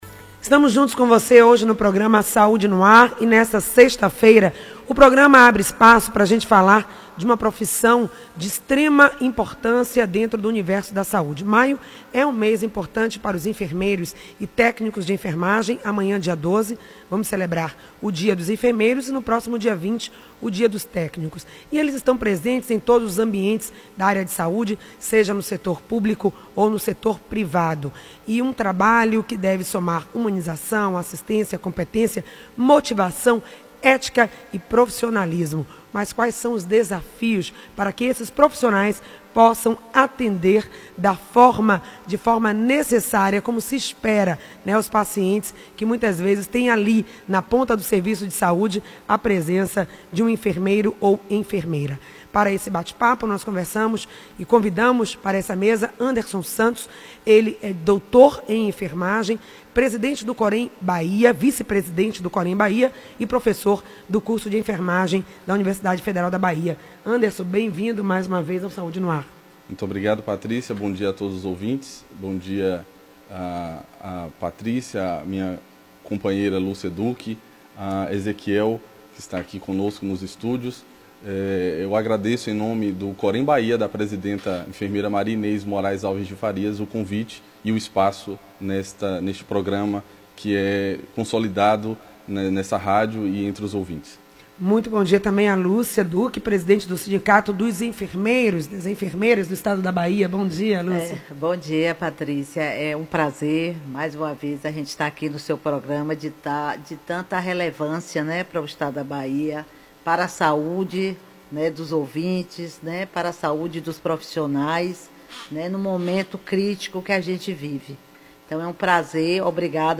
Ouça a entrevista na íntegra ou assista ao vídeo no Facebook